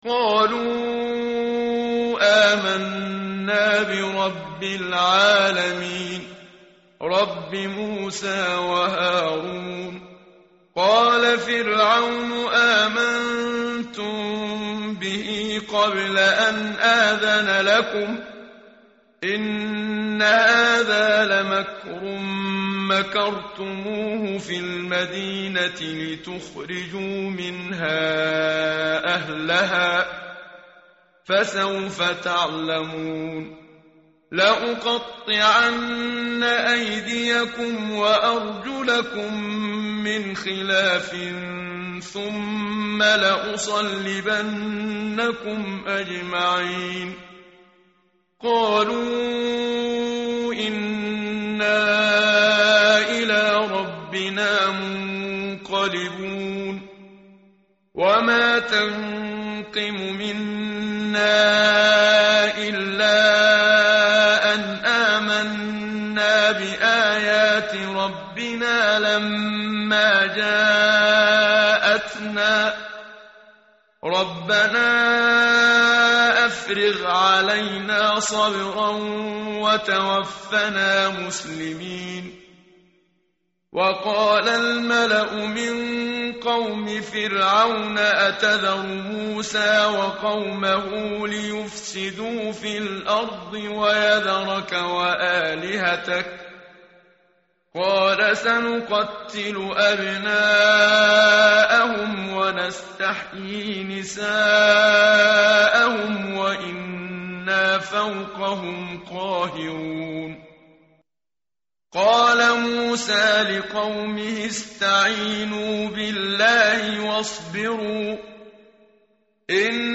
متن قرآن همراه باتلاوت قرآن و ترجمه
tartil_menshavi_page_165.mp3